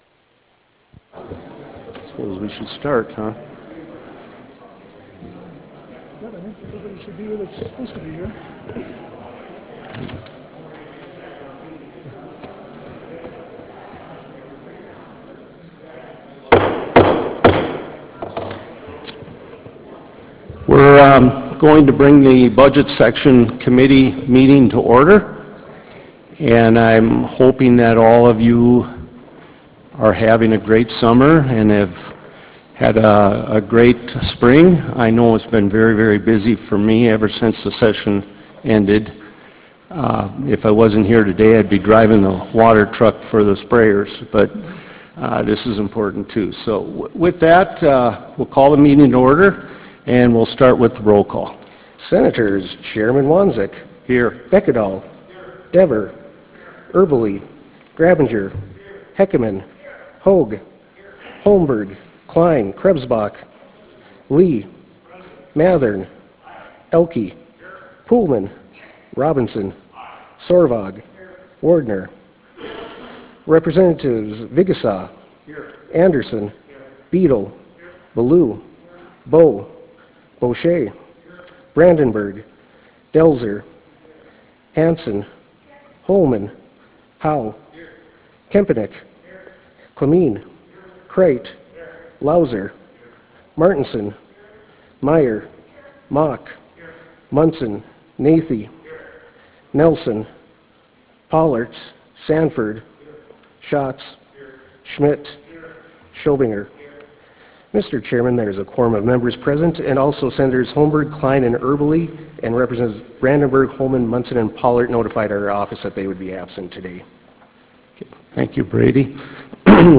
Senate Chamber State Capitol Bismarck, ND United States